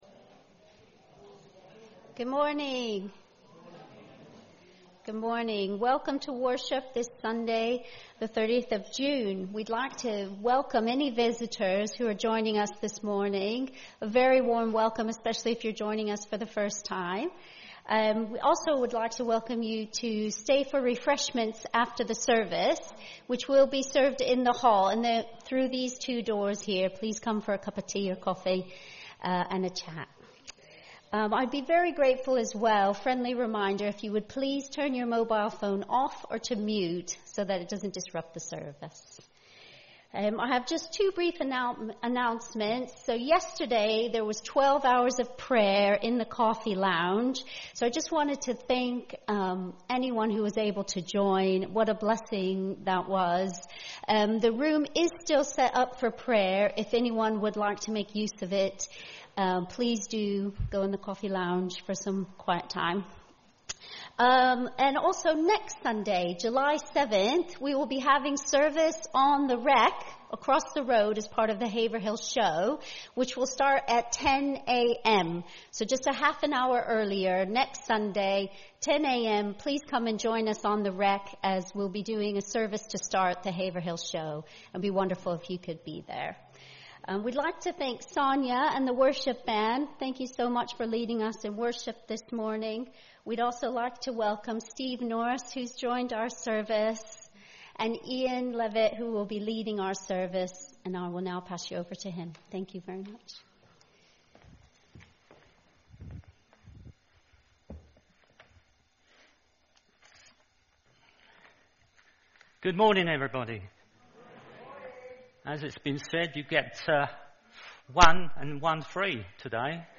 There is no video recording this week but the full service, minus songs, is available as an audio file.